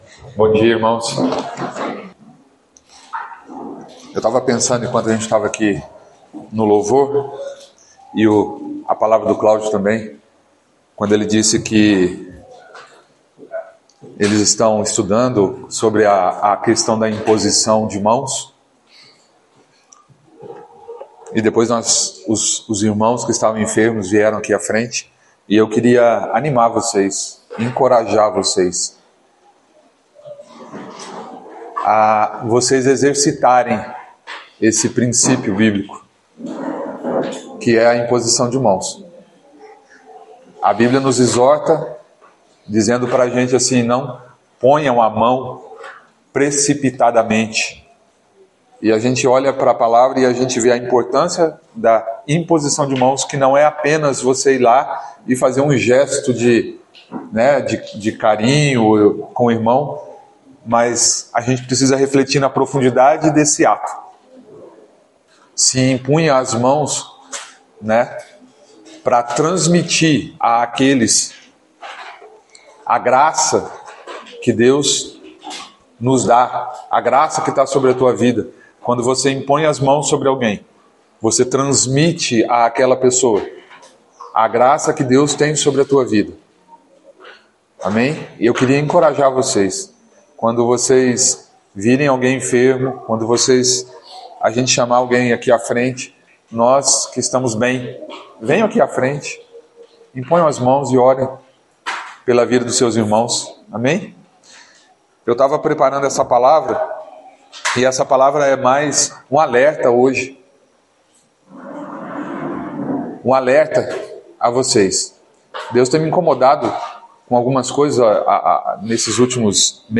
Palavras ministradas O que é a verdade?